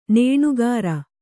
♪ nēṇugara